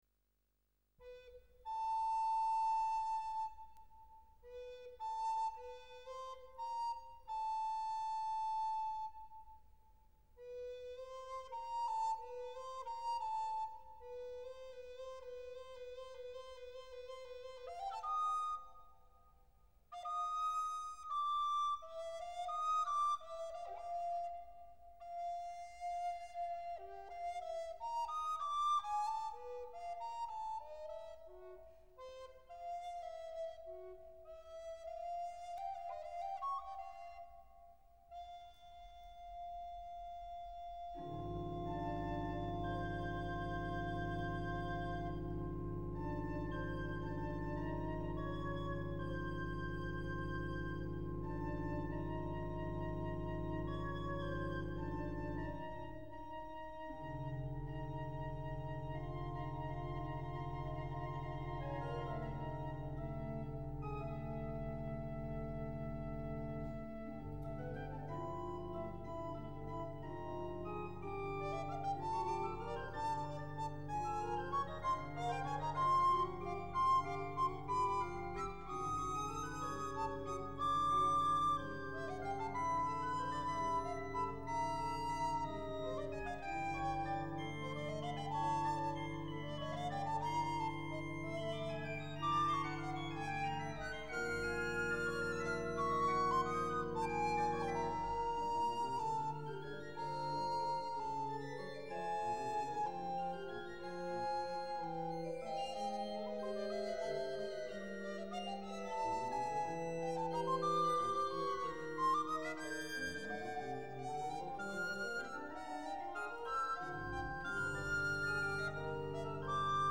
OrgelCD's aus Orgeln im Thüringer Land